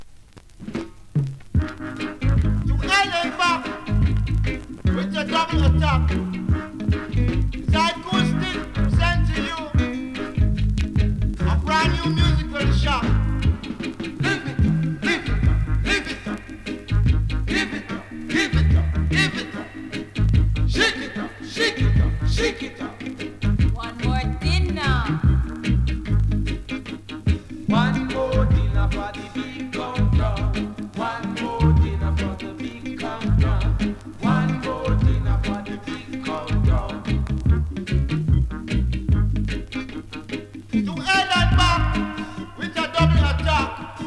SKA〜REGGAE
スリキズ、ノイズかなり少なめの